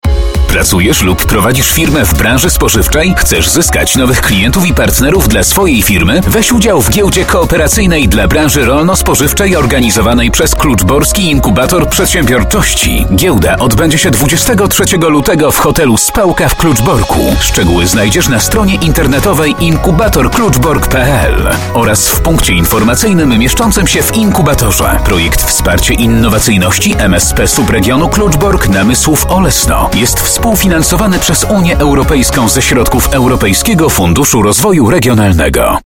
SPOT informacja o Giełdzie emitowany przez Radio Doxa.